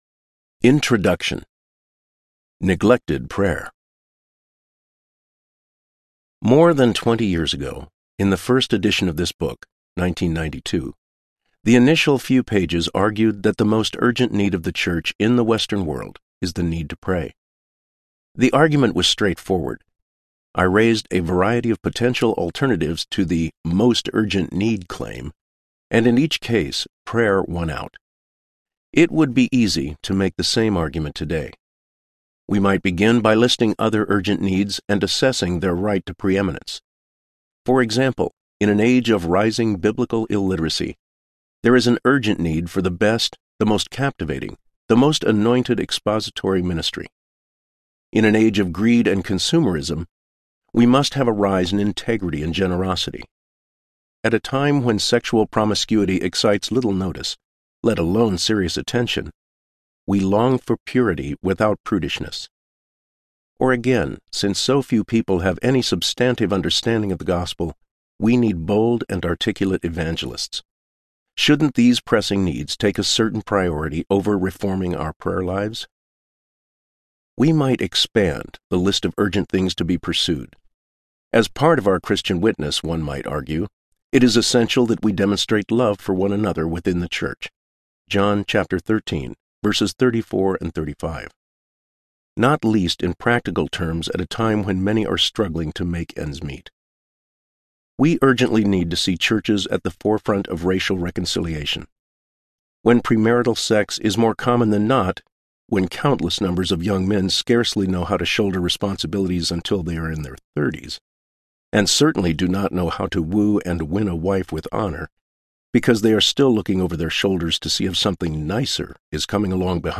Praying with Paul, Second Edition Audiobook
Narrator
8.8 Hrs. – Unabridged